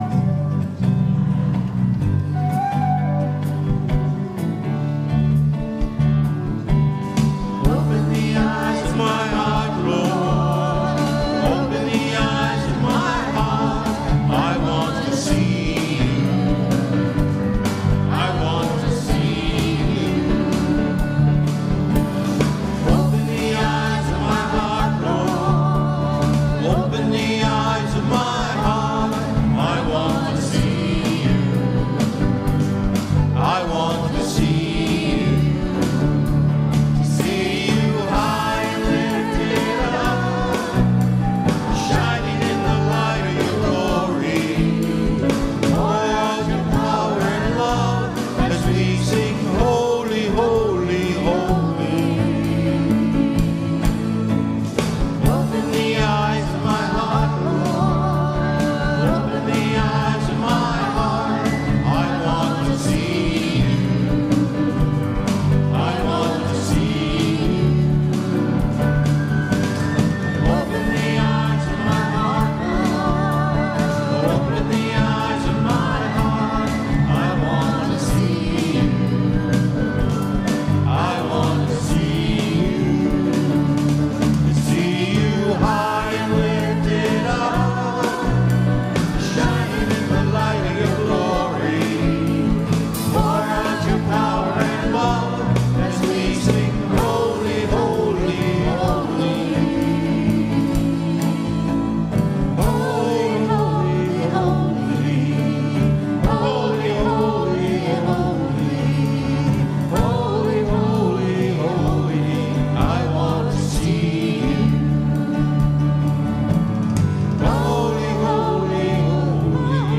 Worship and Sermon audio podcasts
WORSHIP - 10:30 a.m. Second after Pentecost